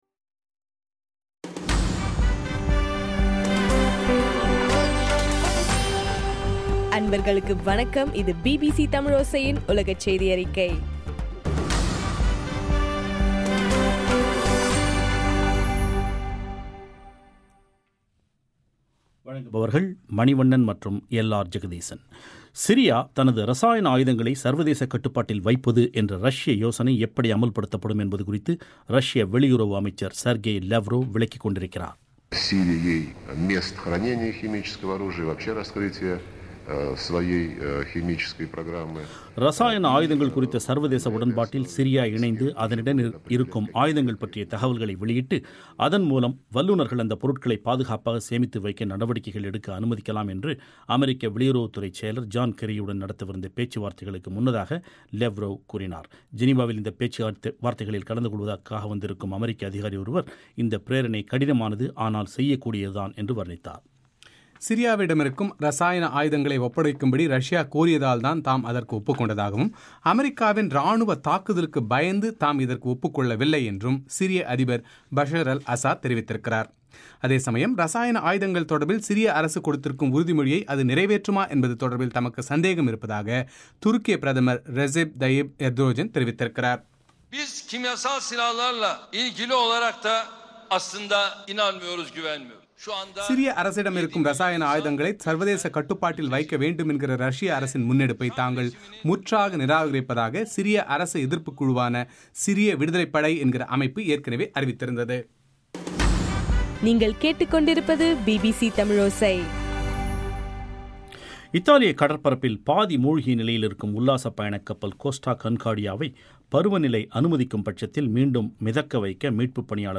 செப்டம்பர் 12 பிபிசியின் உலகச் செய்திகள்